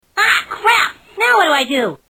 Category: Television   Right: Personal
Tags: South park clips South park Stan Stan sounds Stan from South park